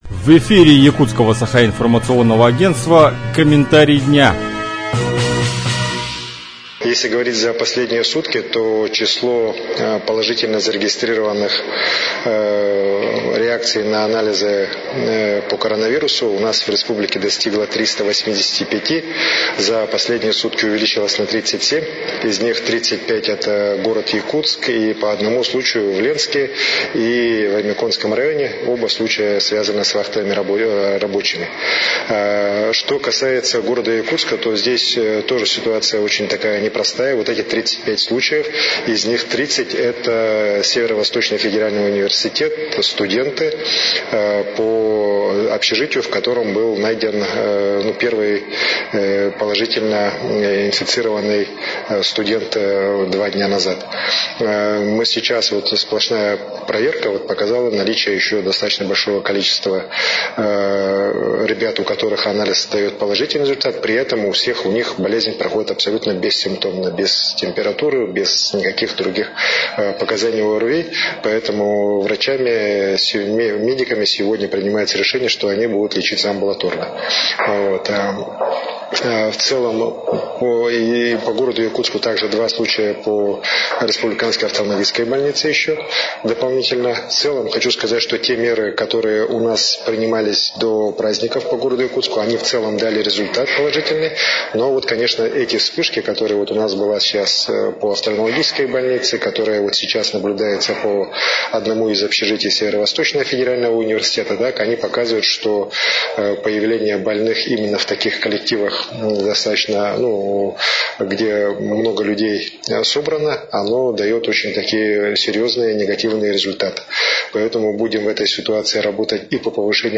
Какова обстановка в Якутии на 7 мая, рассказал глава региона Айсен Николаев.